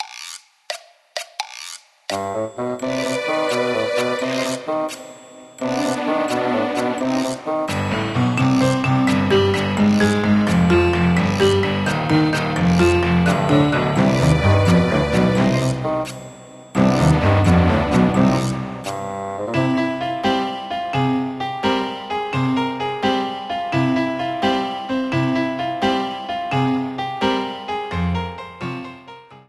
Ripped from the game
Fair use music sample